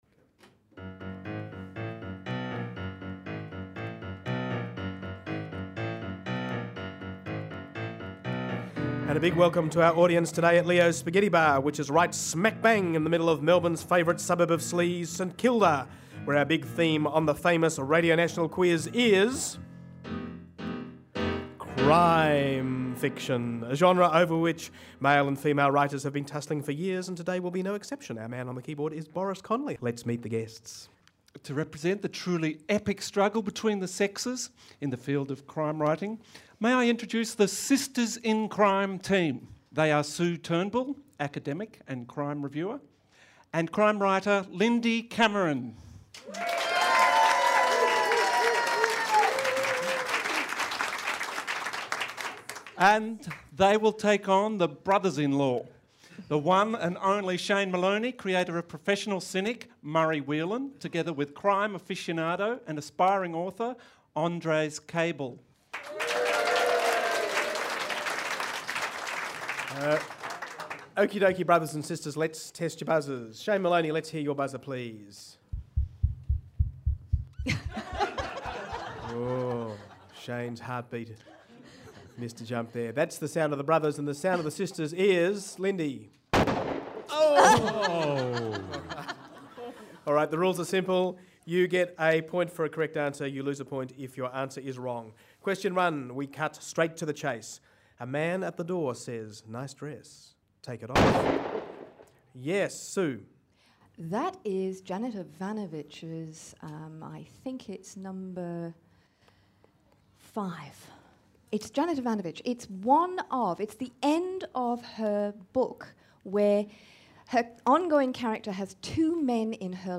half hour quiz show
Recorded in the backroom of at Leo’s Spaghetti Bar, St Kilda before a live audience.